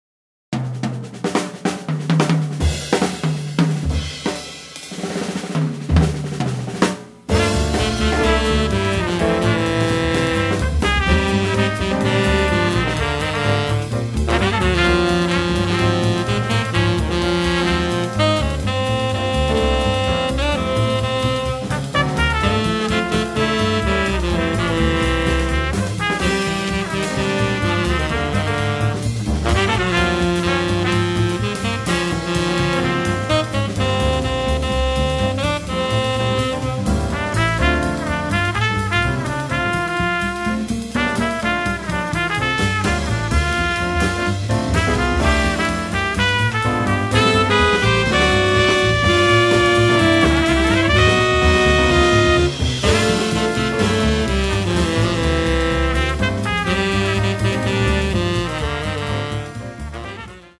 tenor saxophone
acoustic bass
drums
piano
trumpet